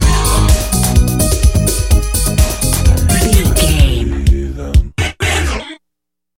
Aeolian/Minor
E♭
synthesiser
Eurodance